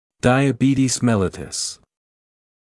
[ˌdaɪə’biːtiːz ‘melɪtəs][ˌдайэ’би:ти:з ‘мэлитэс]сахарный диабет